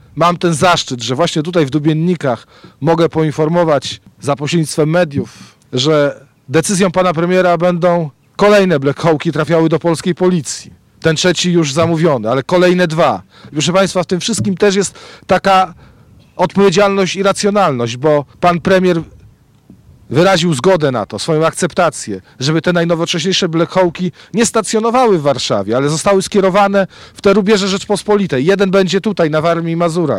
Mateusz Morawiecki, premier Rzeczypospolitej Polskiej wziął w czwartek (28.02.19) udział w otwarciu, reaktywowanego po 19-tu latach, posterunku policji w Dubeninkach.